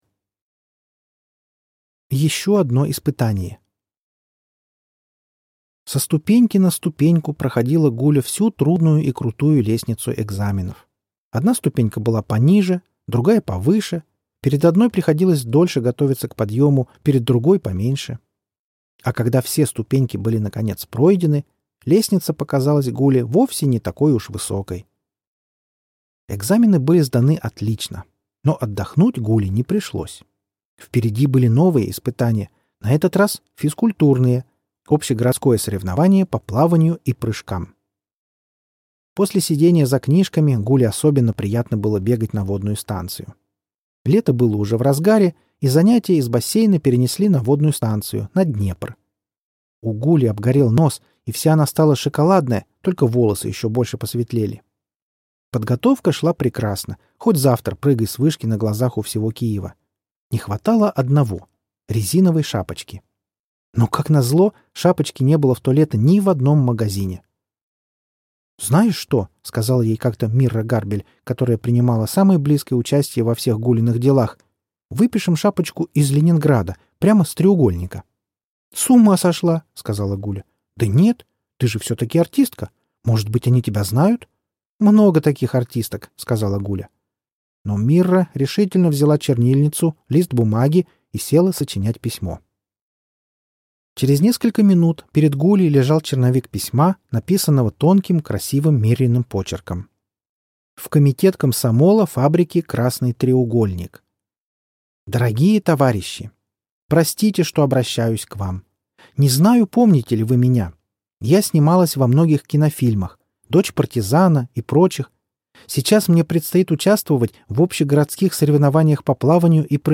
Четвёртая высота - аудио повесть Ильиной - слушать онлайн